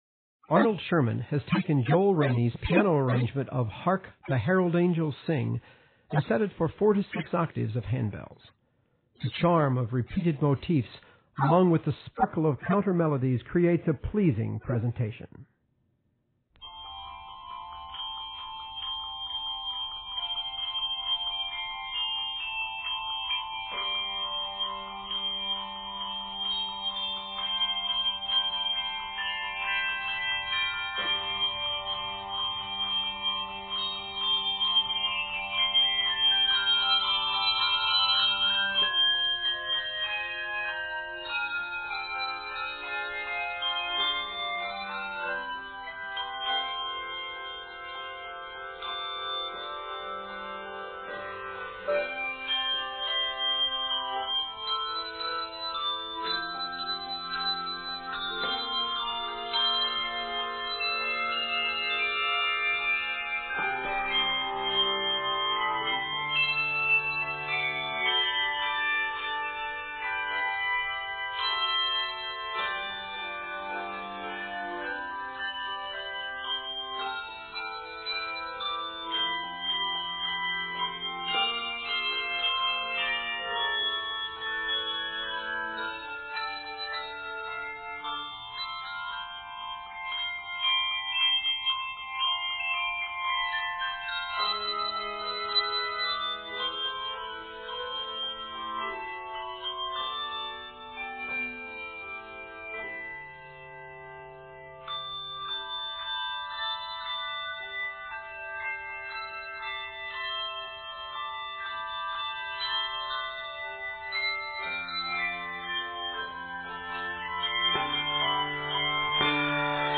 community bell choir